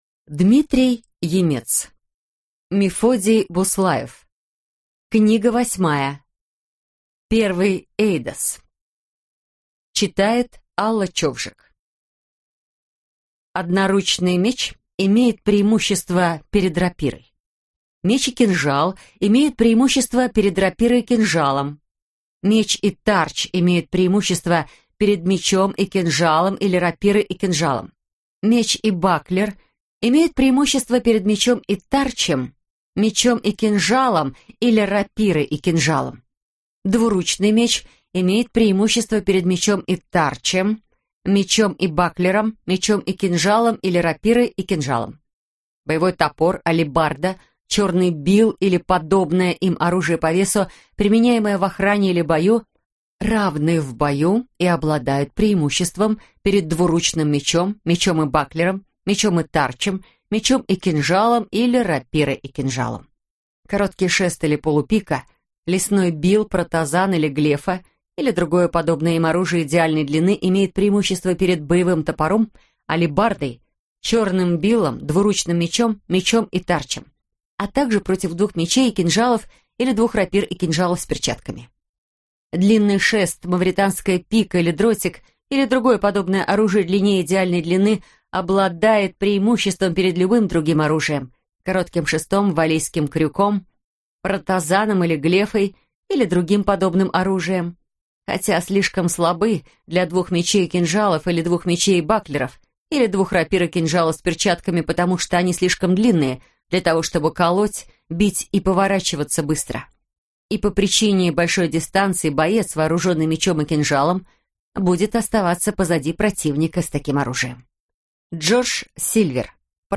Аудиокнига Первый Эйдос | Библиотека аудиокниг
Aудиокнига Первый Эйдос Автор Дмитрий Емец